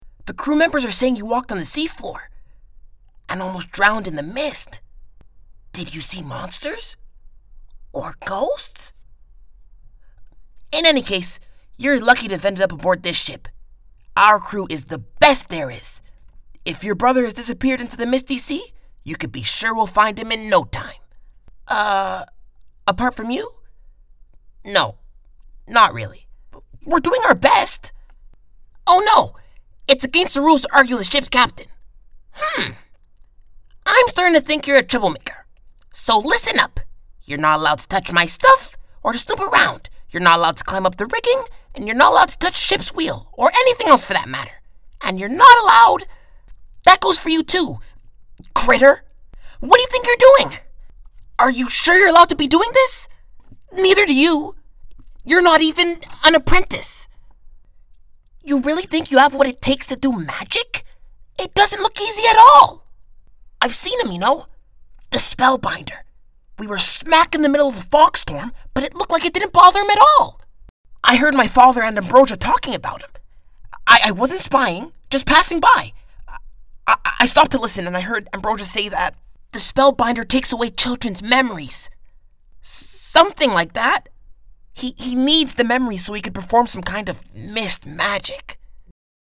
Animation ANG